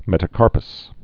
(mĕtə-kärpəs)